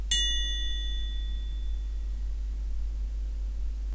Christmas Sound Effects #1
013 ping #1.wav